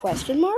Question Mark Téléchargement d'Effet Sonore
Memes Soundboard2 views